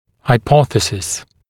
[haɪ’pɔθəsɪs][хай’посэсис]гипотеза, догадка, предположение